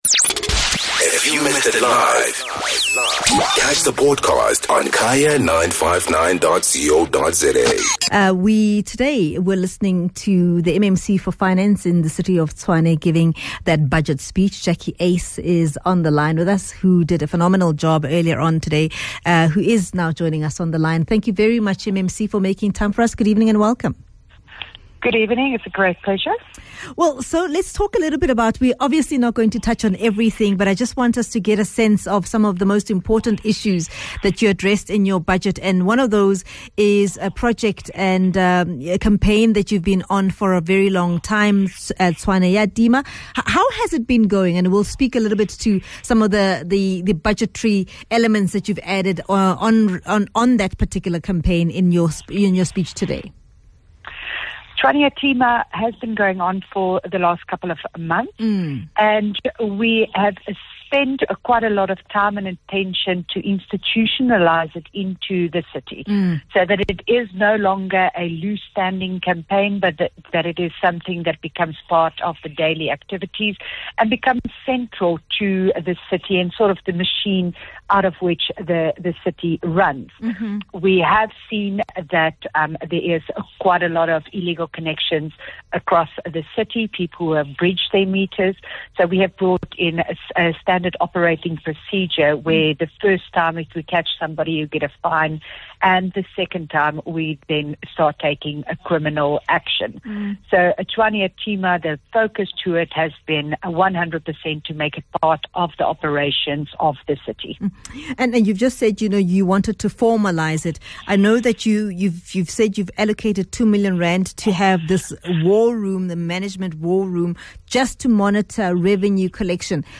Guest: Jacqui Uys - Tshwane MMC for Finance